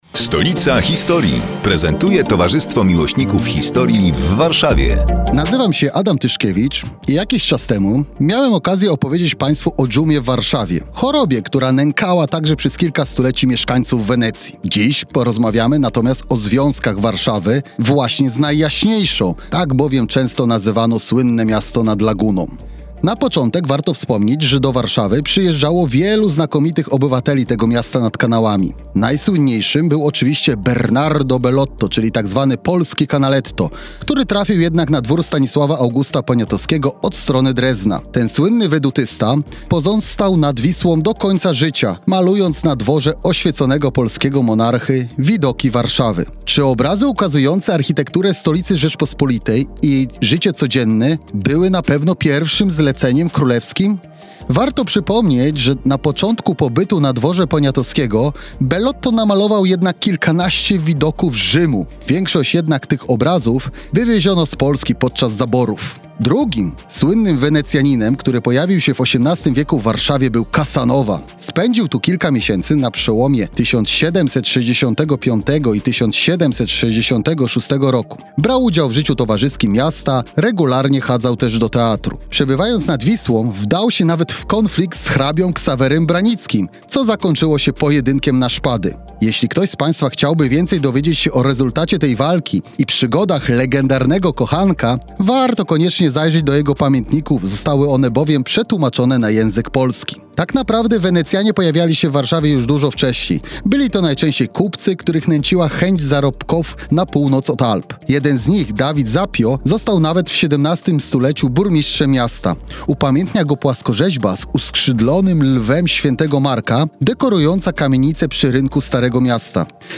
Kolejny felieton pod wspólną nazwą: Stolica historii. Przedstawiają członkowie Towarzystwa Miłośników Historii w Warszawie, które są emitowane w każdą sobotę o 15:15, w nieco skróconej wersji, w Radiu Kolor.